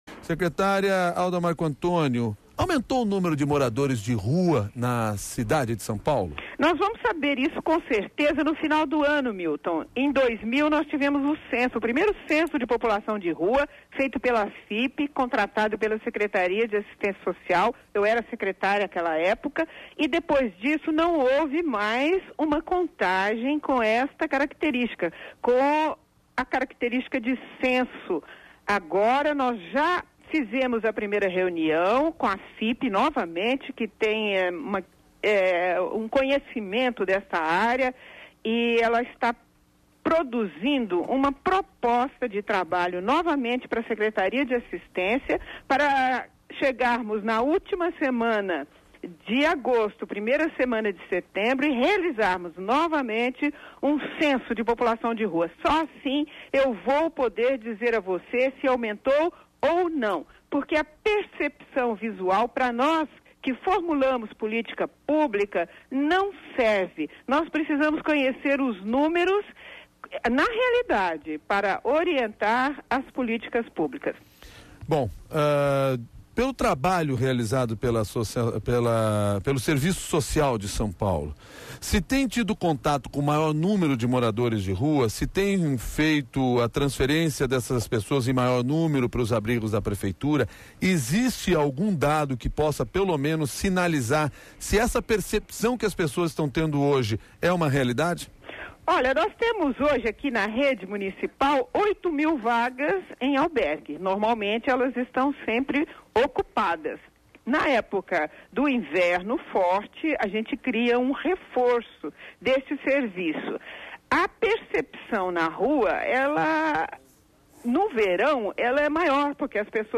Ouça a entrevista da secretária Alda Marcoantonio, ao CBN SP